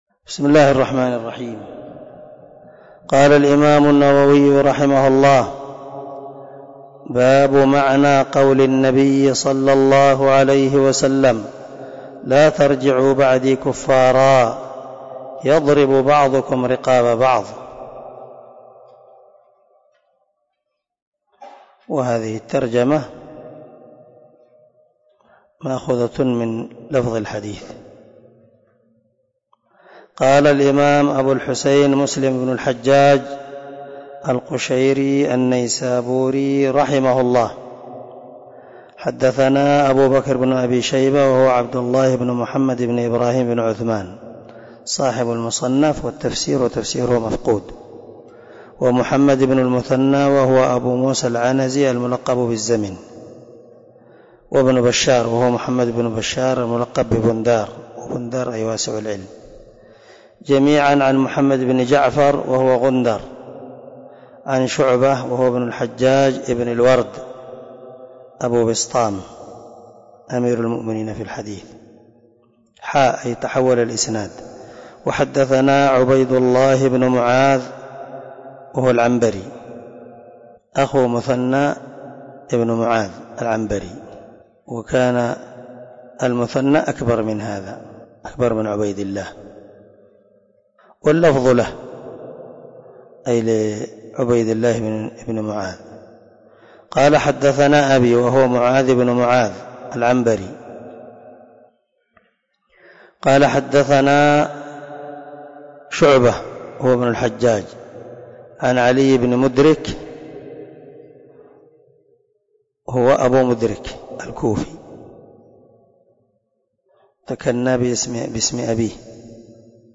سلسلة_الدروس_العلمية